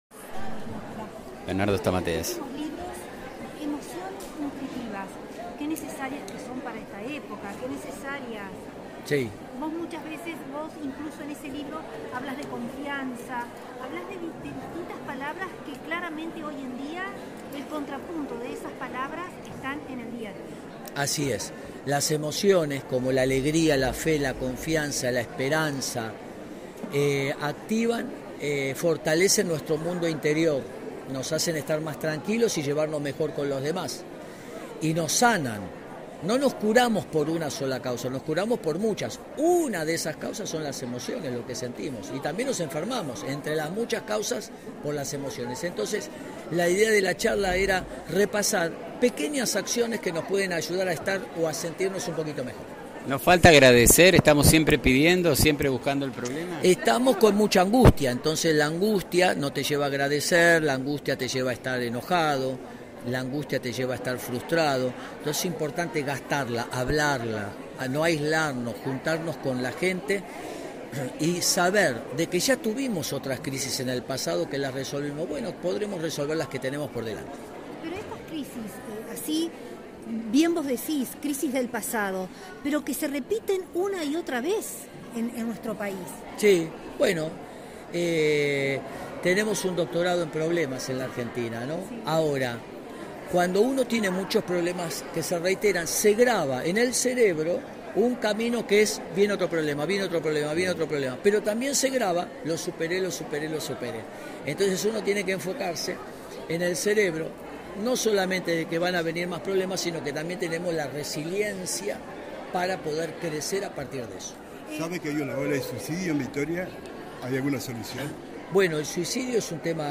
Las emociones como la alegría, la fe, la confianza y la esperanza son los disparadores a partir de los cuales sanamos. Esto fue lo que en resumen, explico Bernardo Stamateas en conferencia de prensa en el Cine Teatro Victoria.